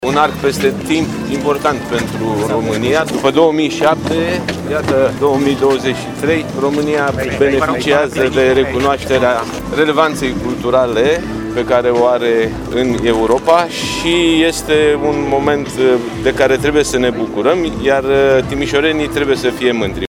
Nicolae Ciucă, la deschiderea programului Timișoara 2023: România se bucură din nou de recunoașterea relevanței sale culturale
Zeci de oficialități din țară și străinătate au participat la ceremonia de gală dedicată deschiderii Capitalei Culturale.